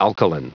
Prononciation audio / Fichier audio de ALKALINE en anglais
Prononciation du mot alkaline en anglais (fichier audio)